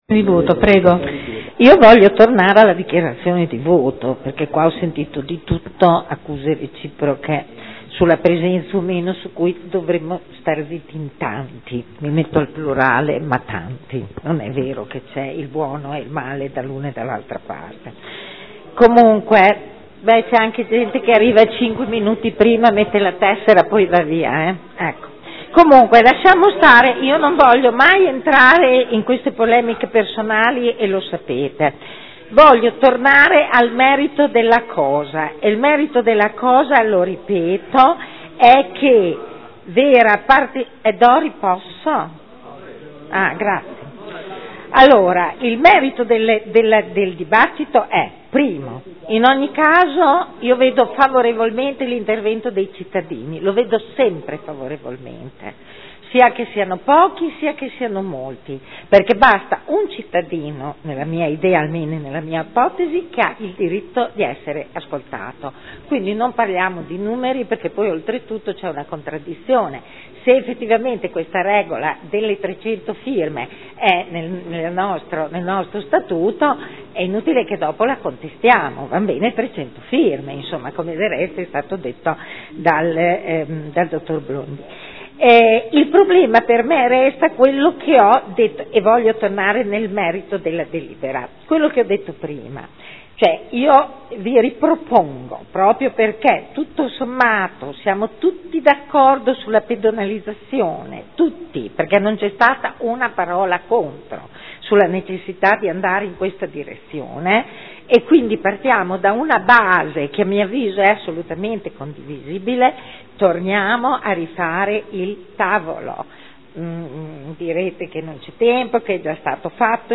Dichiarazione di voto. Proposta di deliberazione di iniziativa popolare presentata dal Comitato cittadini, residenti, commercianti, professionisti, lavoratori dipendenti e fruitori del Centro Storico di Modena, ai sensi dell’art. 3 del Regolamento sugli Istituti di Partecipazione, avente per oggetto: “Rinvio del progetto di riqualificazione urbana di Piazza Roma e di sua pedonalizzazione”